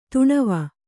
♪ tuṇava